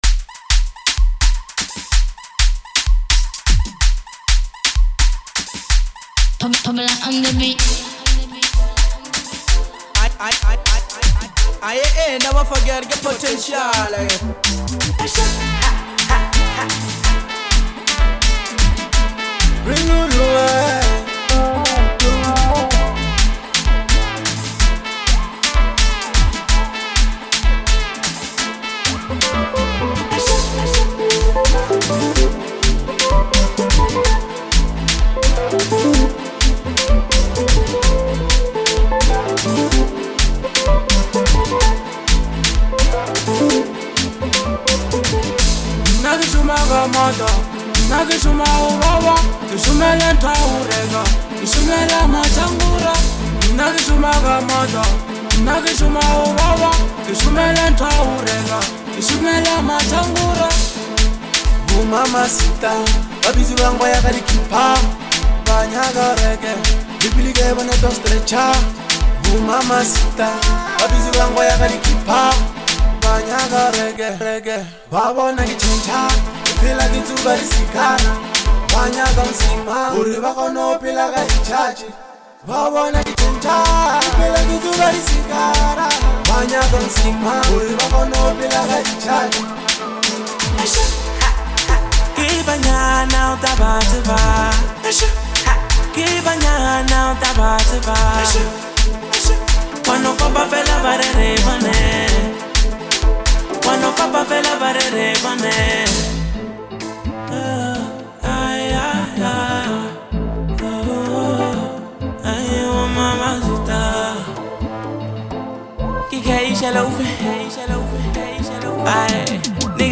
a high energy Afro house anthem